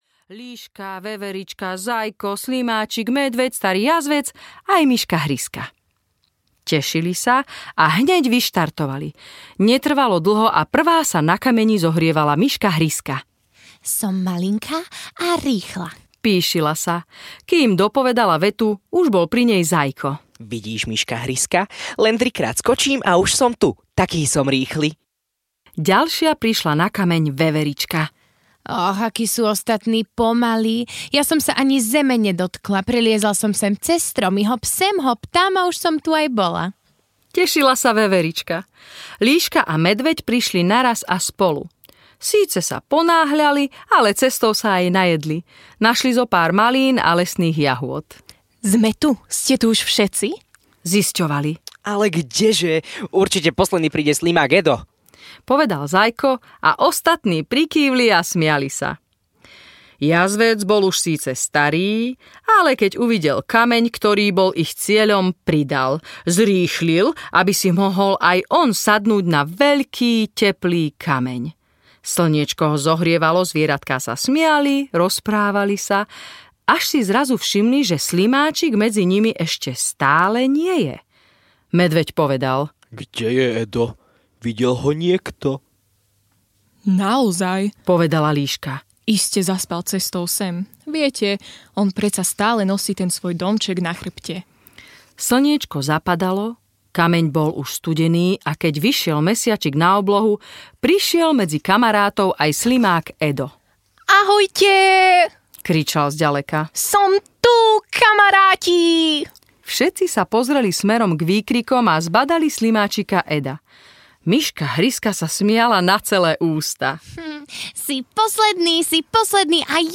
Ukázka z knihy
Znelka - harfa pred každou rozprávkou dáva deťom najavo, aby sa uložili do postele a pozorne počúvali príbeh, ktorý sa odohráva hlavne v prostredí zvieratiek.